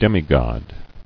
[dem·i·god]